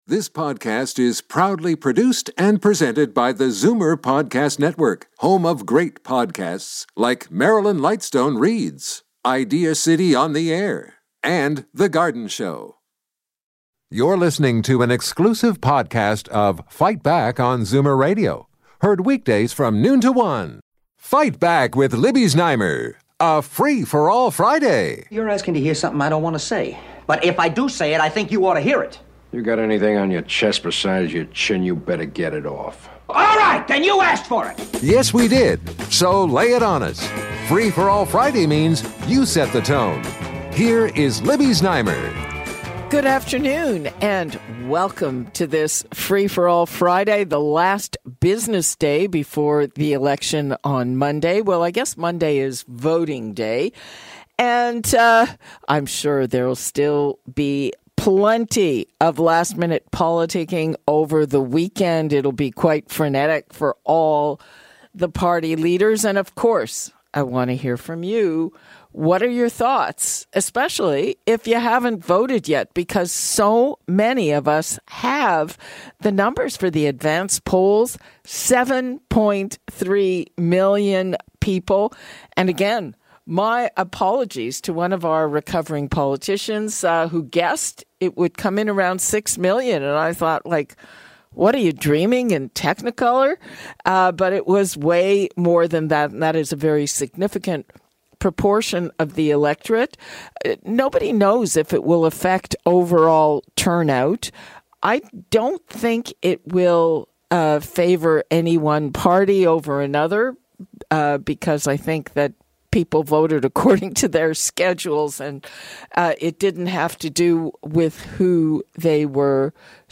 It's the last Free For All Friday before election day on April 28. In a highly engaging conversation, our listeners weighed in with their thoughts on Pierre Poilievre versus Mark Carney.